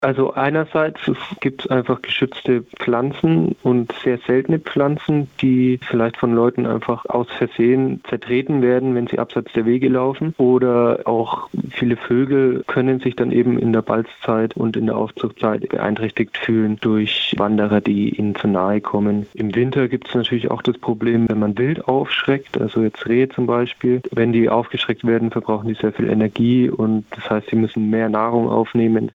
Interview: Die Gefahr vom Wandern auf illegalen Wegen - PRIMATON